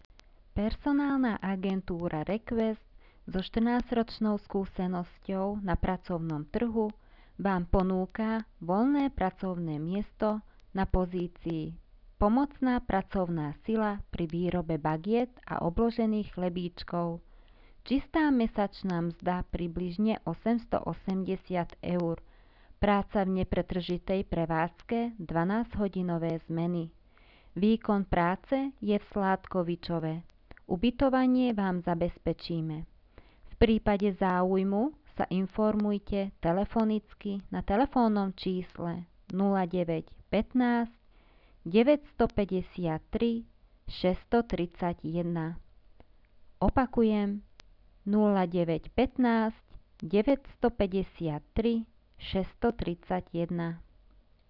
Hlásenia v mestskom rozhlase